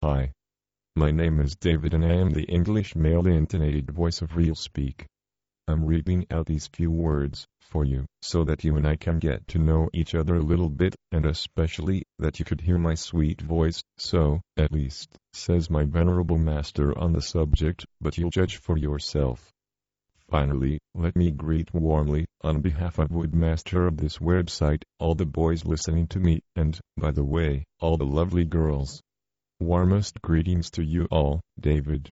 �couter la d�monstration de David; homme; anglais am�ricain; fourni avec Nuance (Anciennement Scansoft) OmniPage Pro (Version 14.0)